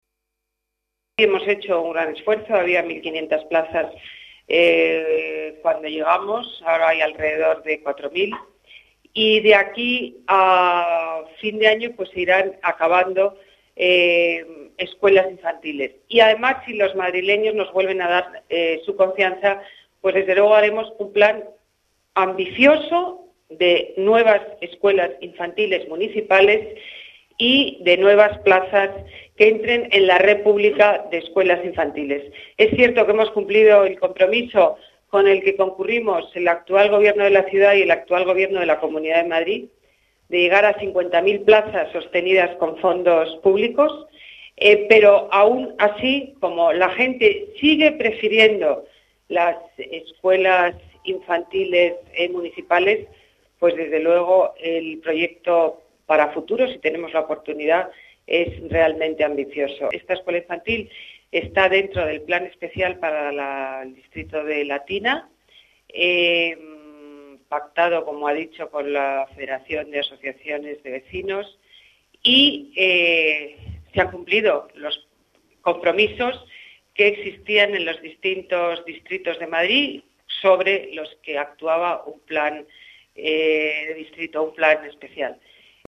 Nueva ventana:Declaraciones de Ana Botella en la inauguración de la Escuela Infantil Municipal "Muñico"